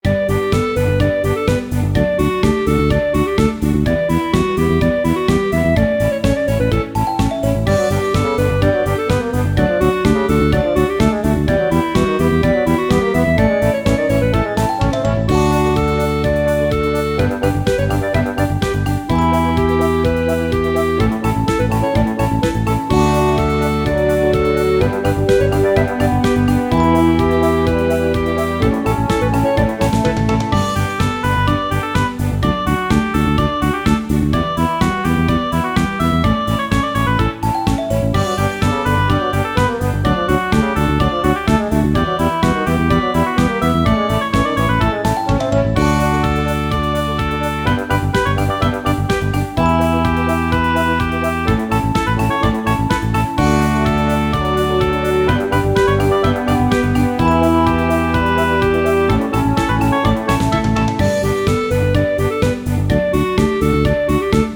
イメージ：明るい 軽やか   カテゴリ：RPG−街・村・日常